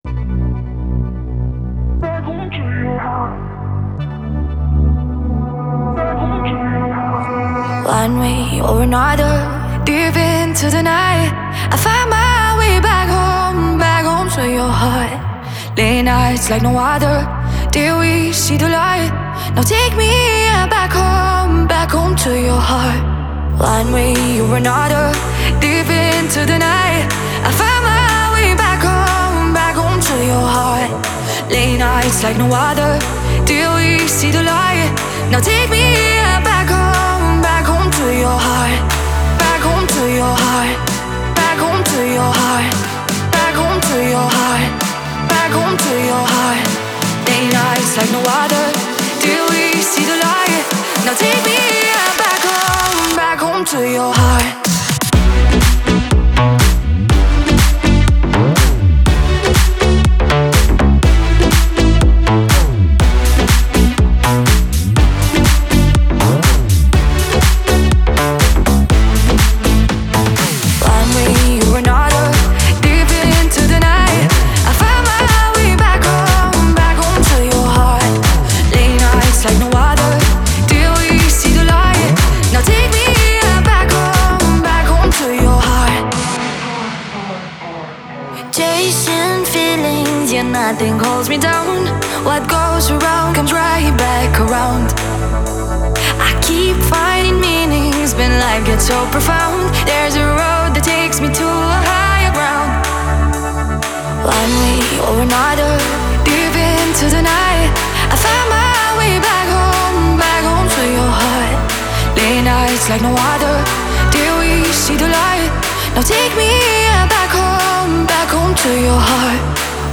это энергичная композиция в жанре электронного попа